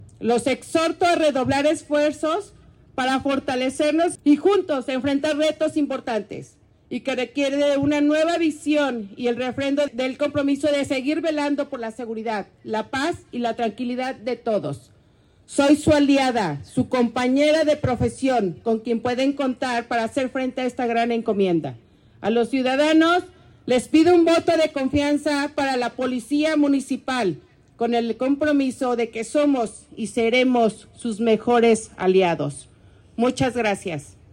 María del Consuelo Cruz Galindo, nueva secretaría de seguridad ciudadana